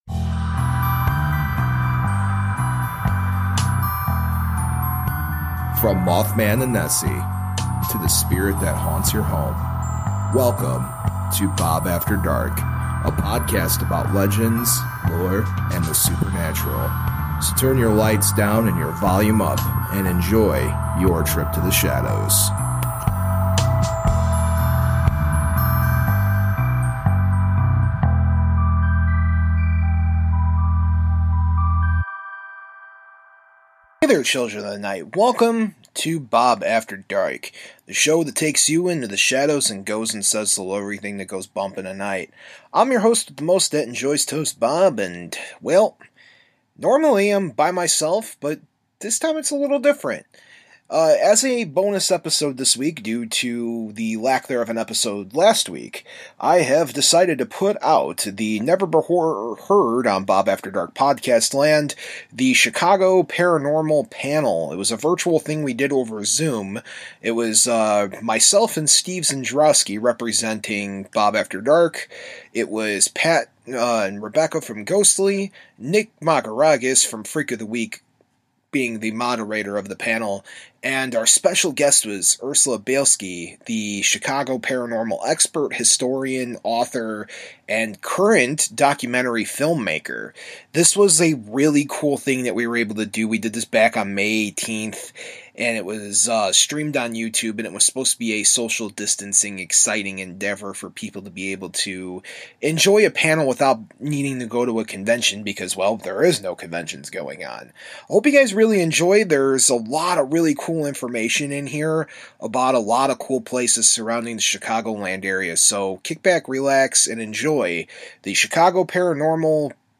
031 - Chicago Paranormal Panel